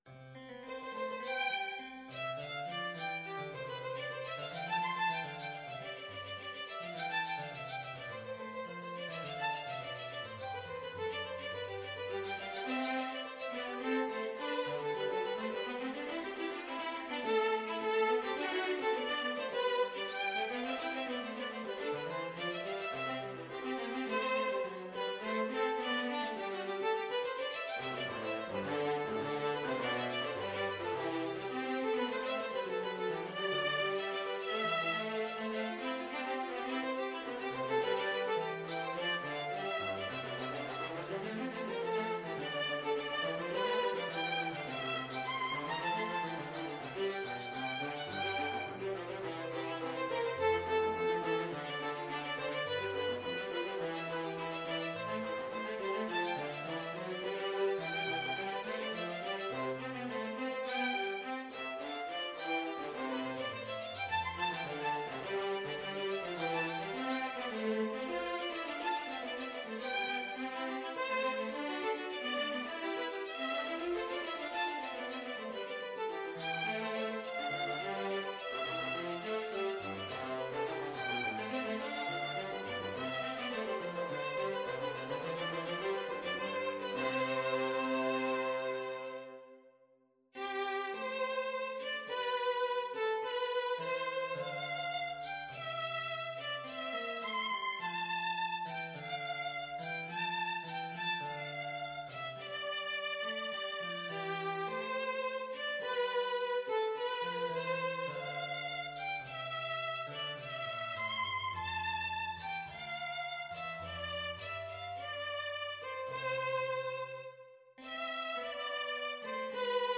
Free Sheet music for Mixed Trio
ViolinViola da GambaHarpsichord (Figured Bass)
Classical (View more Classical Mixed Trio Music)